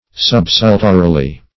Sub*sul"to*ri*ly, adv. [R.]